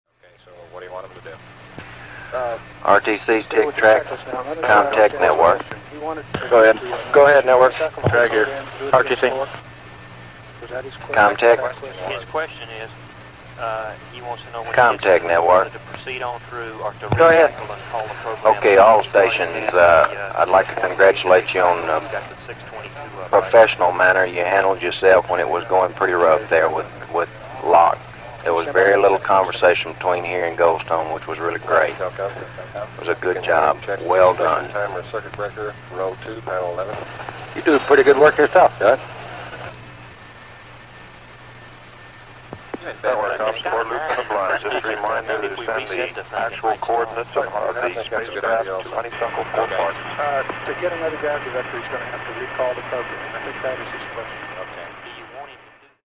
Both recordings contain the Flight Director’s loop and Air/Ground (Columbia, Eagle and Capcom).
Filters were applied to remove hum, and tape hiss was removed as much as possible – without removing noise that was heard on the circuits.
420kb mp3 clip – after the landing.